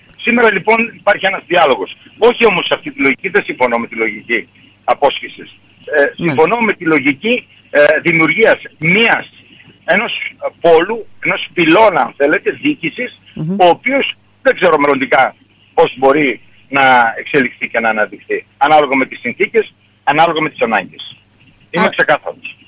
Την διαφωνία του σχετικά με την δημιουργία διοικητικής διαίρεσης στον βόρειο Έβρο εξέφρασε σήμερα με δηλώσεις του στην ΕΡΤ Ορεστιάδας, ο Αντιπεριφερειάρχης  Έβρου Δημήτρης Πέτροβιτς.